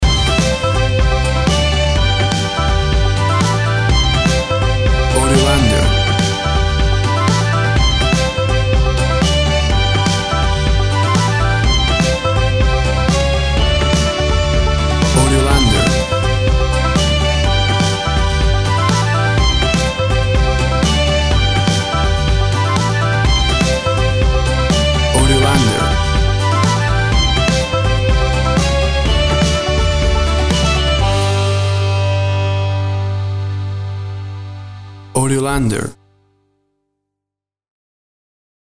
Tempo (BPM) 125